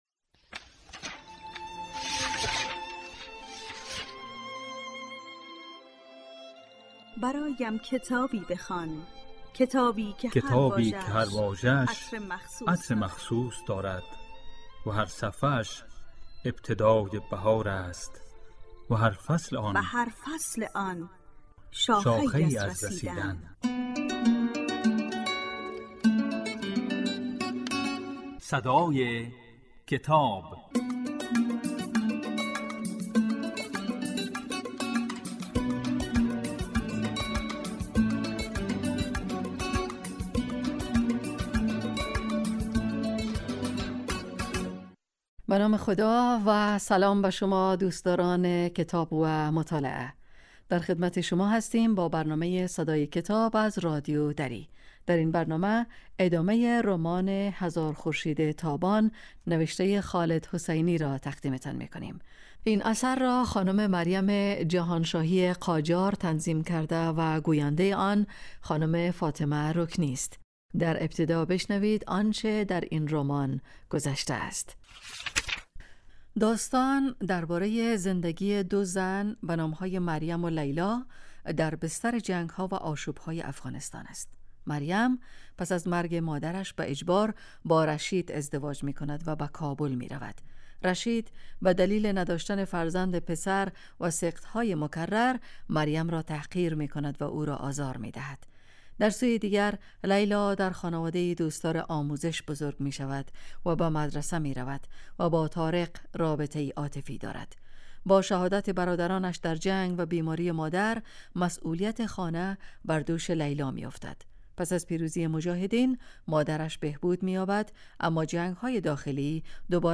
در این برنامه، به دنیای کتاب‌ها گام می‌گذاریم و آثار ارزشمند را می‌خوانیم.